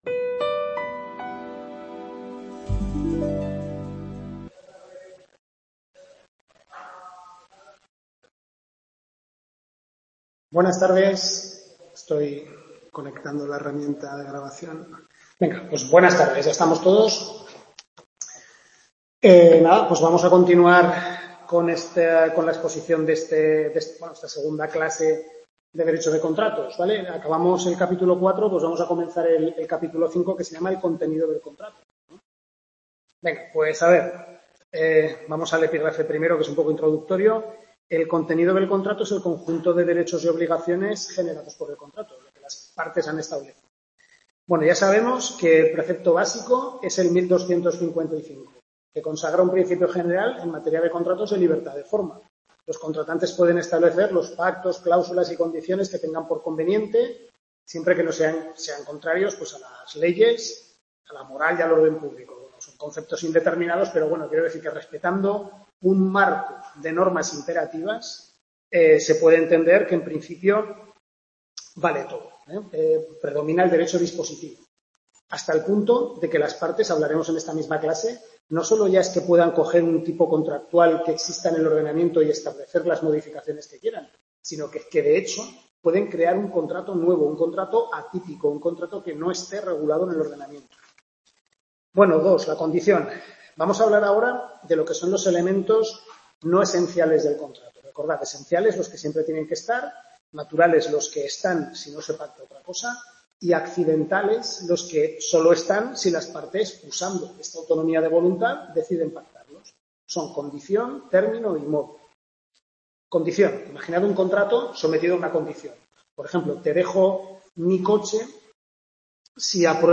Tutoría 2/6 Civil II, segundo cuatrimestre (Contratos)